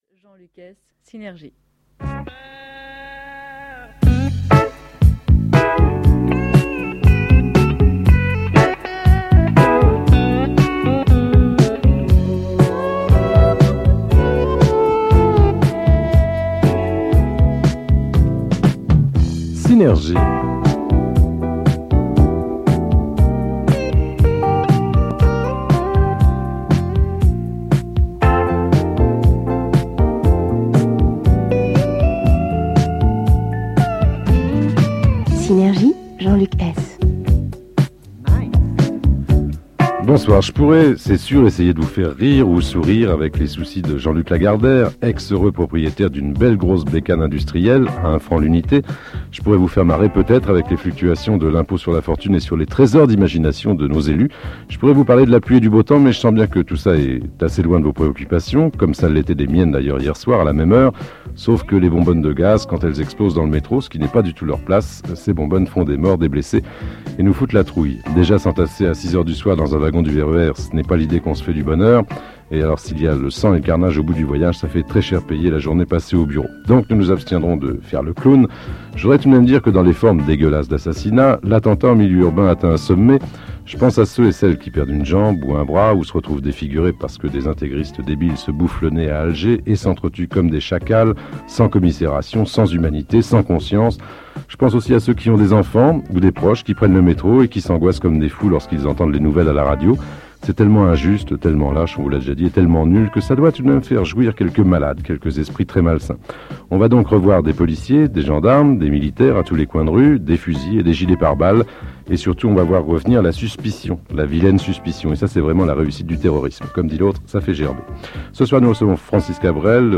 interviewe